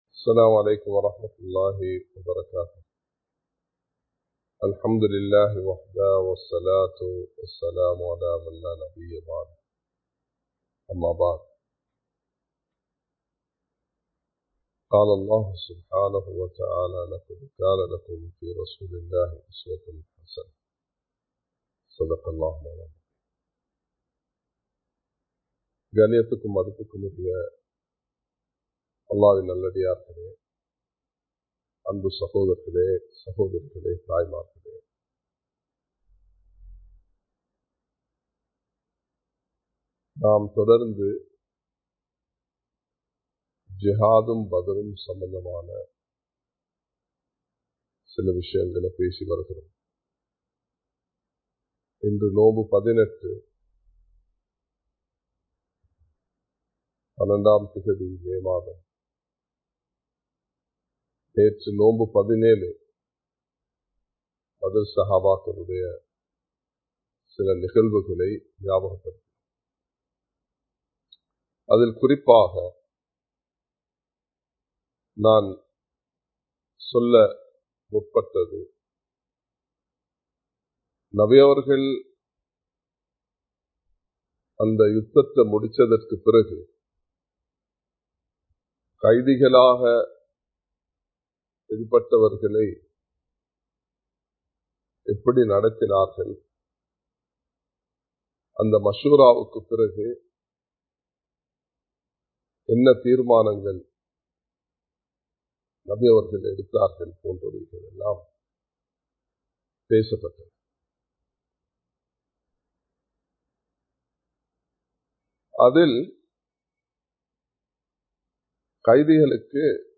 பத்ர் சொல்லும் பாடம் (பகுதி 02) | Audio Bayans | All Ceylon Muslim Youth Community | Addalaichenai
Live Stream